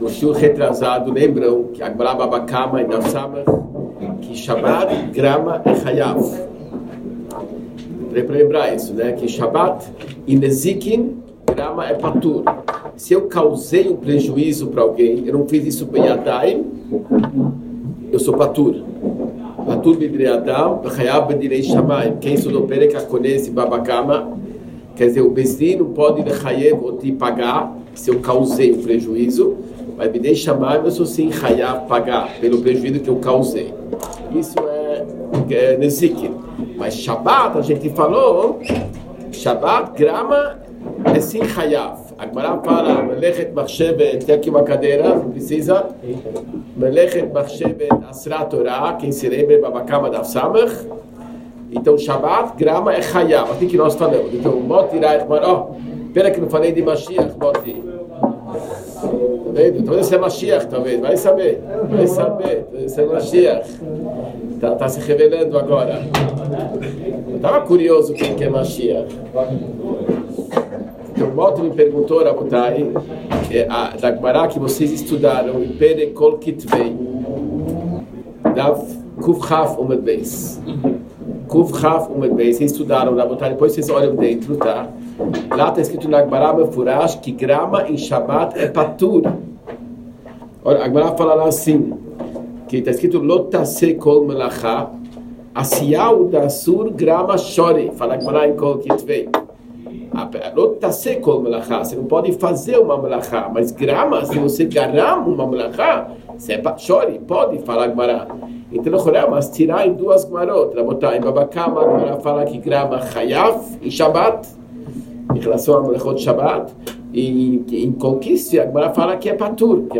Shiur 39 Melachot – Parte 3